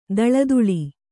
♪ daḷaduḷi